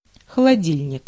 Ääntäminen
US : IPA : [ɹɪ.ˈfɹɪdʒ.ə.ˌɹeɪ.tɚ]